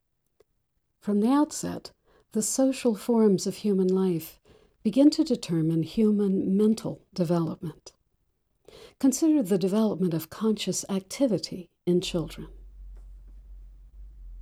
When I raised the Audacity program gain to 60%, that seemed to be the best volume, and that is what I’m attaching here.
Most of that hollow distortion is Zoom et al trying to keep the host’s voice our of your replies. Did you notice once there at the beginning, the host interrupted you briefly and your voice dipped in volume?